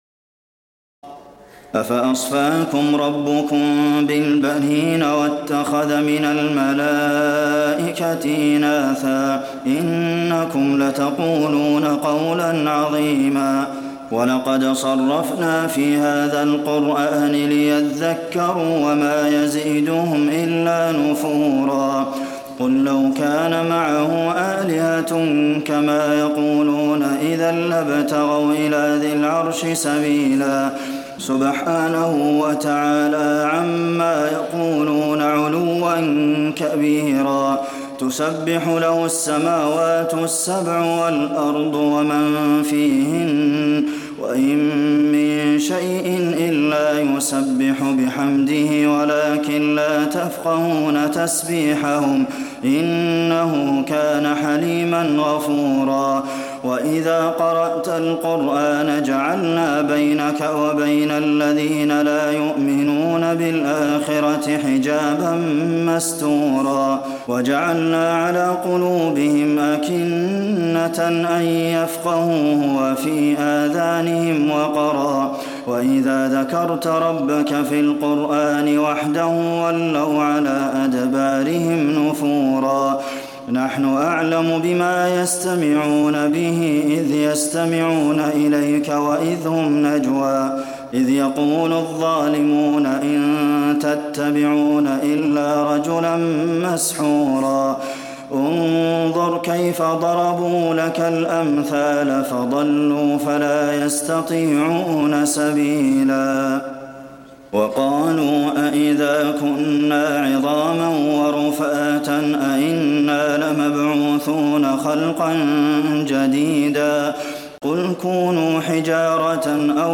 تراويح الليلة الرابعة عشر رمضان 1426هـ من سورة الإسراء (40-111) Taraweeh 14 st night Ramadan 1426H from Surah Al-Israa > تراويح الحرم النبوي عام 1426 🕌 > التراويح - تلاوات الحرمين